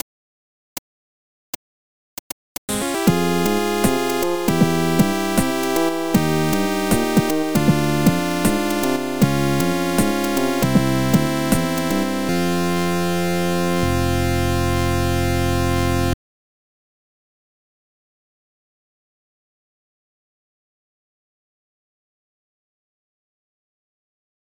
78bpm